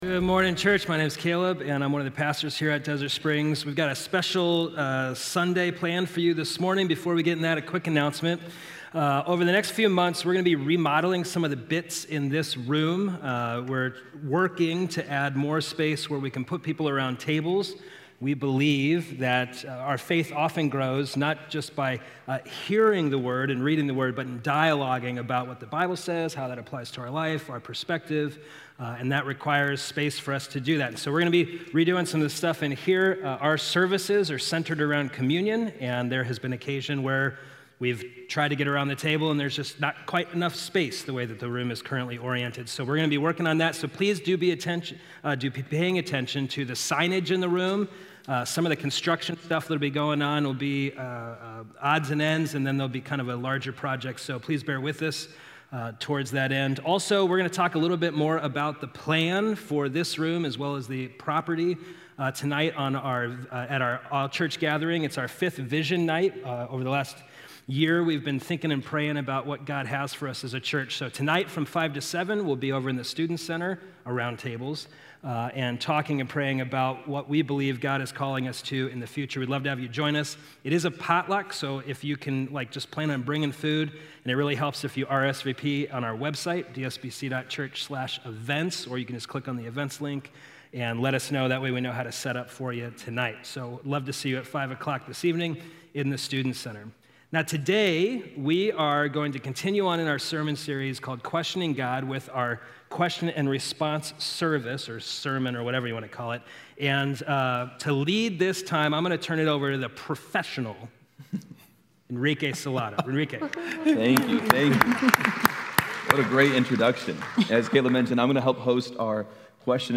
Q&A Service